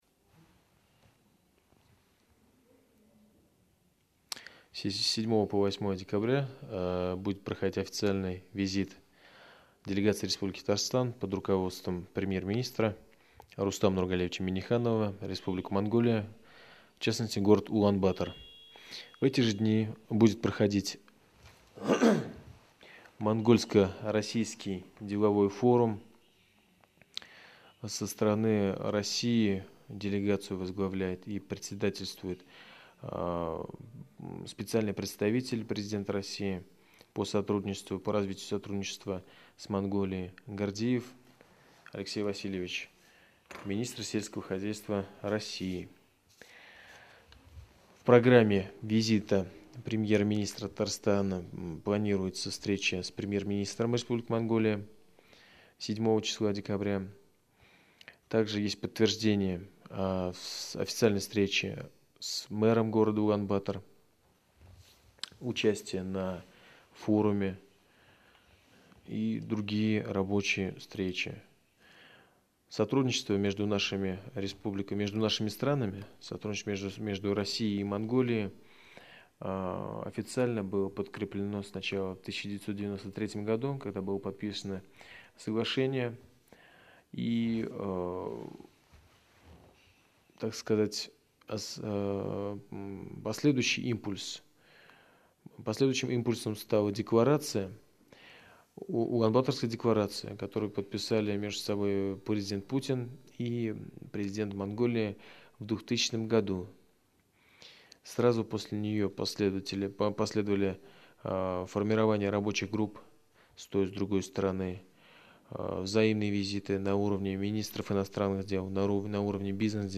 Аудиорепортаж